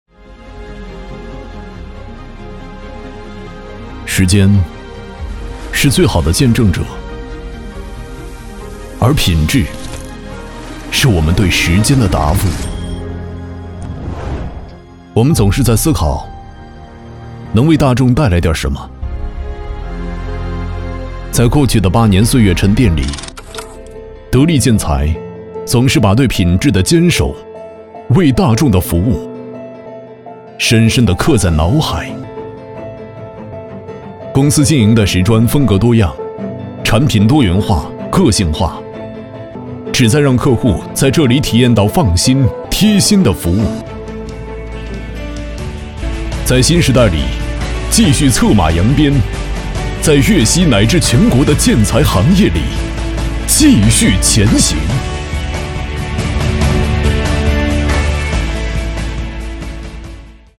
国语配音
男558-广告-时间的答案.mp3